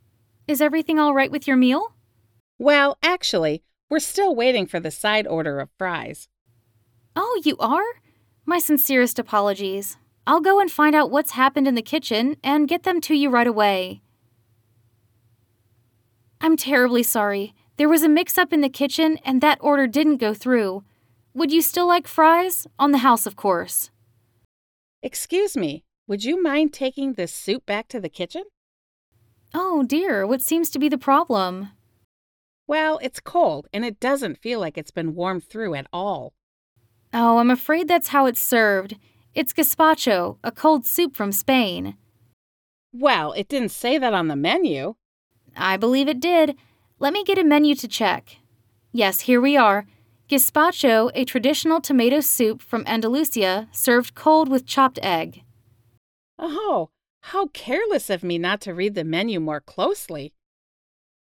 RH5 5.1_conversation_full.mp3